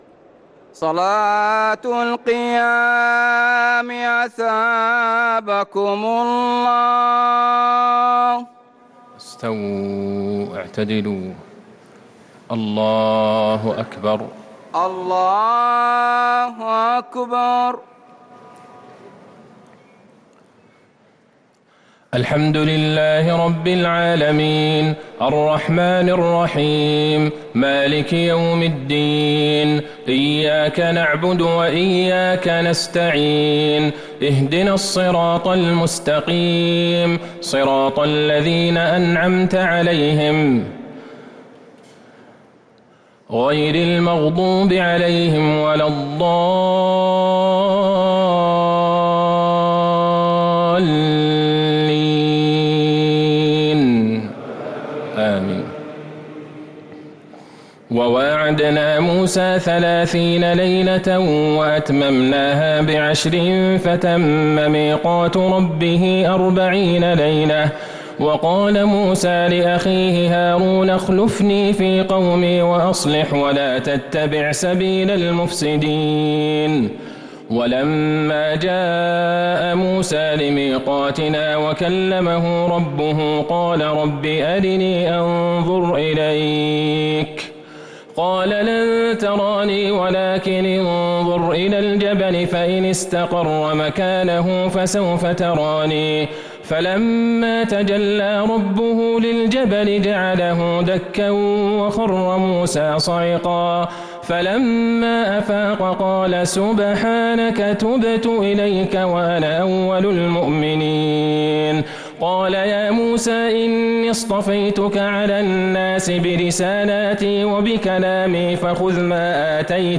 صلاة التراويح ليلة 12 رمضان 1443 للقارئ عبدالله البعيجان - الثلاث التسليمات الأولى صلاة التراويح